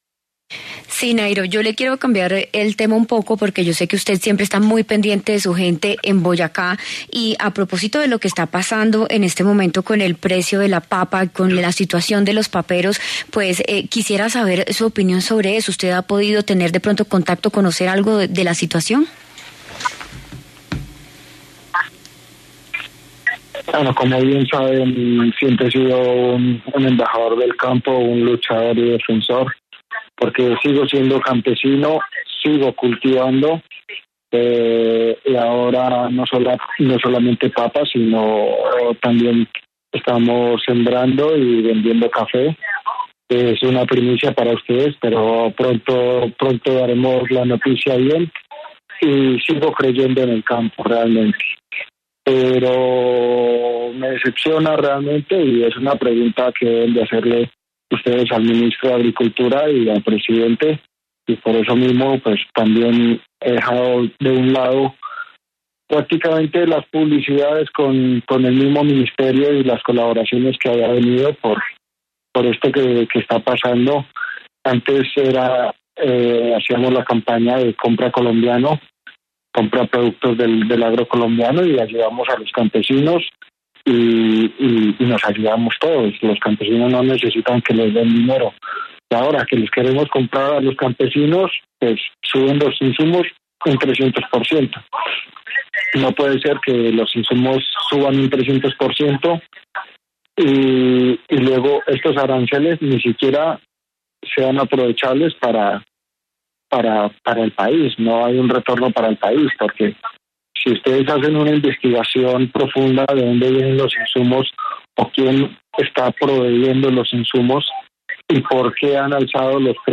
El ciclista colombiano Nairo Quintana habló en La W, con Julio Sánchez Cristo, sobre su triunfo en el Tour de la Provence y lo que viene en el 2022 en materia deportiva.